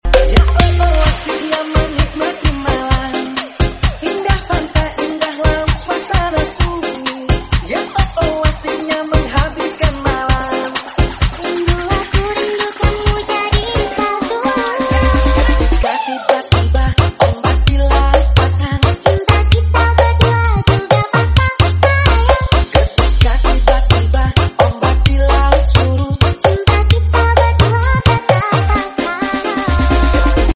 Parade sound expo psss sapuran 5-6 juli 2025 di lap sapuran wonosobo .